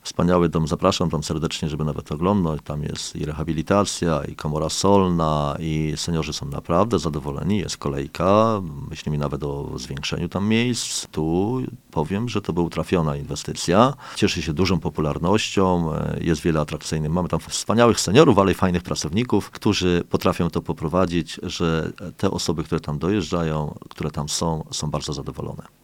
Mówi wójt gminy Tuszów Narodowy, Andrzej Głaz.